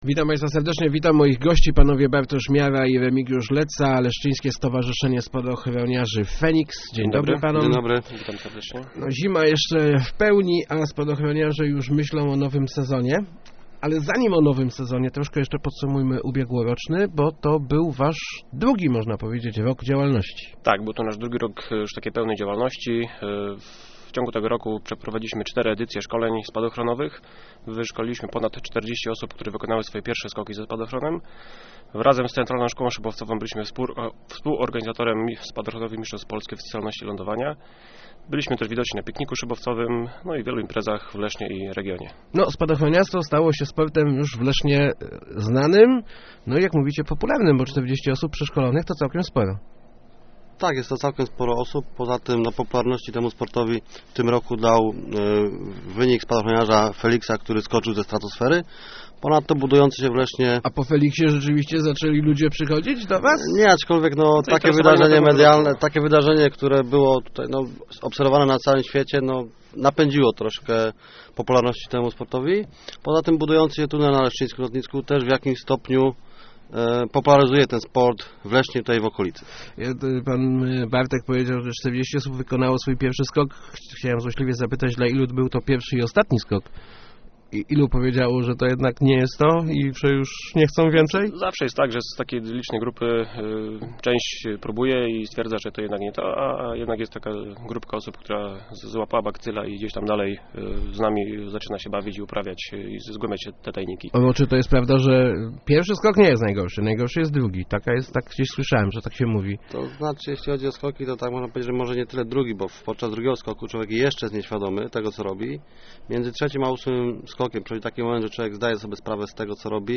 Chcemy dorównać najlepszym w kraju - mówili w Rozmowach Elki